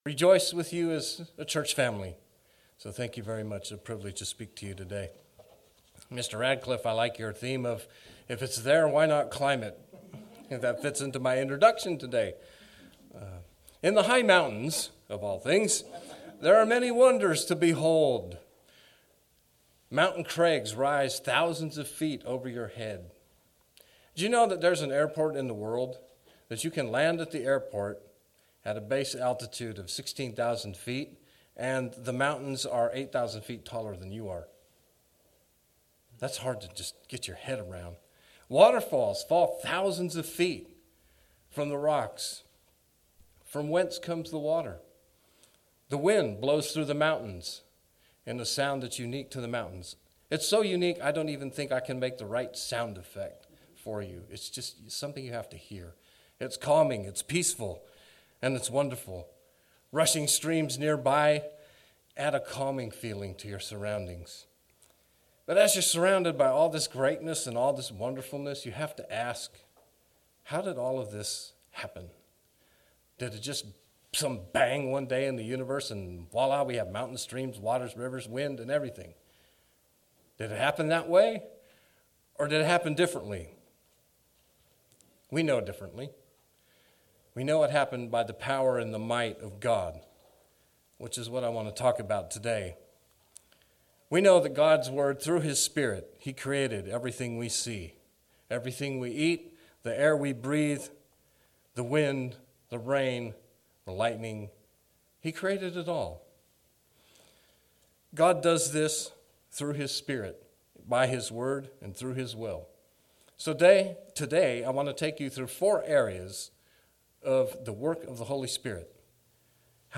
Given in Omaha, NE